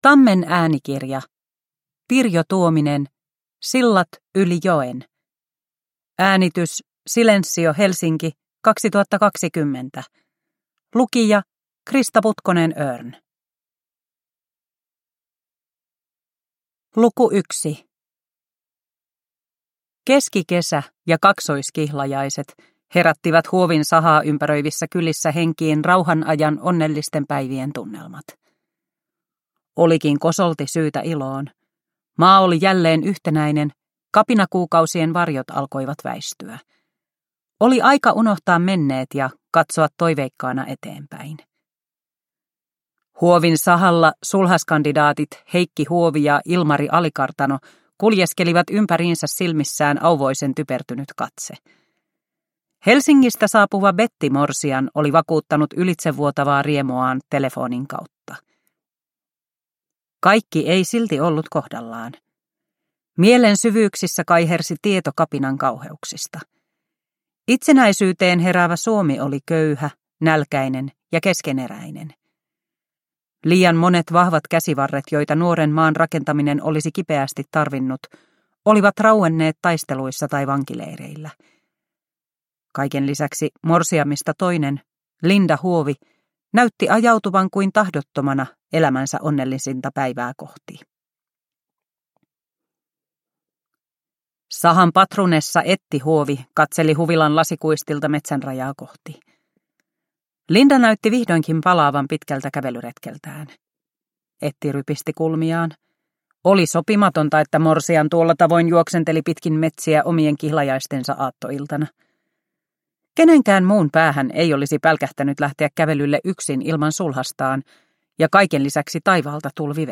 Sillat yli joen – Ljudbok – Laddas ner